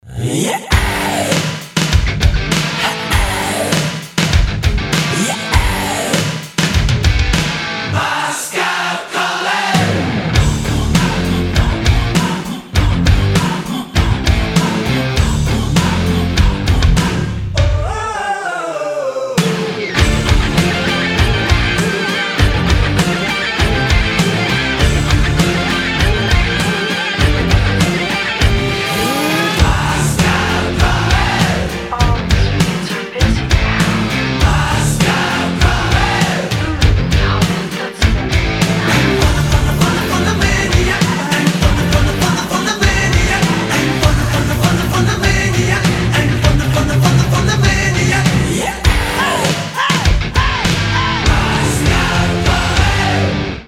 Рок рингтоны
Брутальные , Glam metal